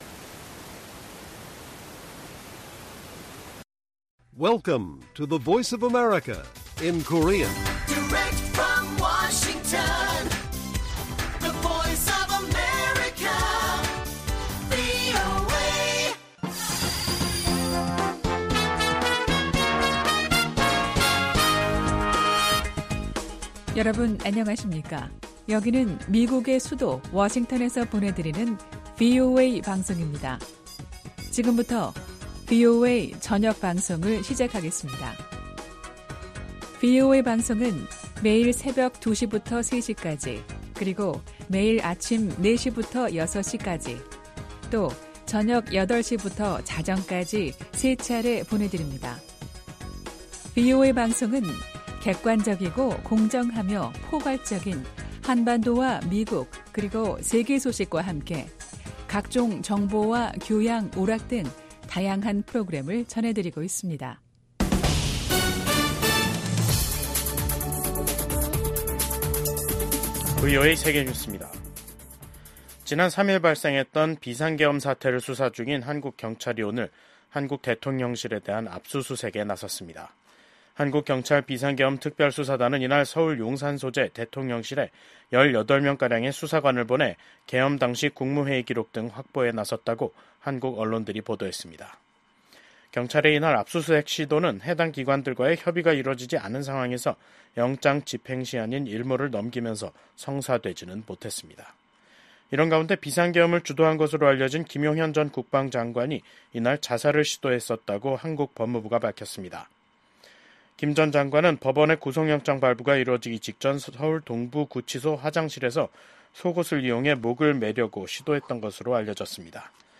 VOA 한국어 간판 뉴스 프로그램 '뉴스 투데이', 2024년 12월 11일 1부 방송입니다. 12.3 비상계엄 사태를 수사하고 있는 한국 사법당국은 사건을 주도한 혐의를 받고 있는 김용현 전 국방부 장관을 구속했습니다. 한국의 비상계엄 사태 이후 한국 민주주의가 더욱 강해졌다고 미국 인권 전문가들이 평가했습니다. 윤석열 한국 대통령이 현 상황에서 효과적으로 한국을 통치할 수 있을지 자문해야 한다고 미국 민주당 소속 매릴린 스트릭랜드 하원의원이 밝혔습니다.